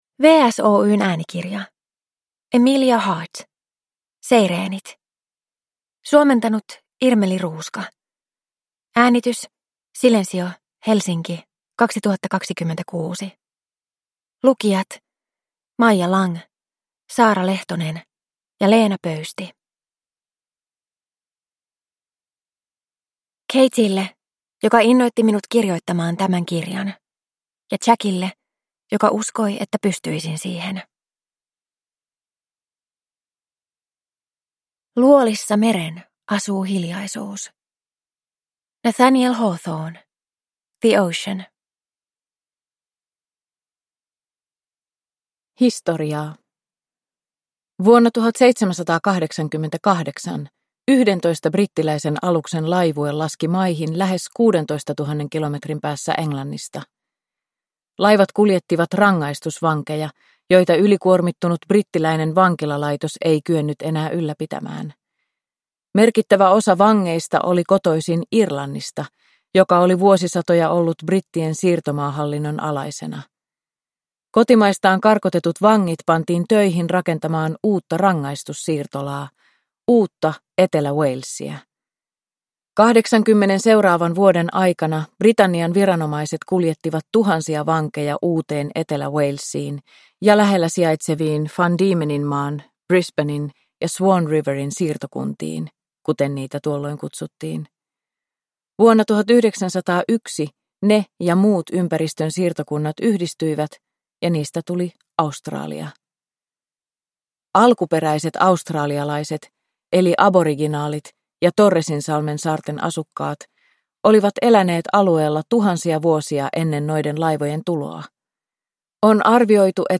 Seireenit – Ljudbok